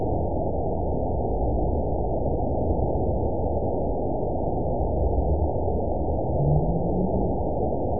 event 920402 date 03/23/24 time 10:56:59 GMT (1 year, 1 month ago) score 7.09 location TSS-AB02 detected by nrw target species NRW annotations +NRW Spectrogram: Frequency (kHz) vs. Time (s) audio not available .wav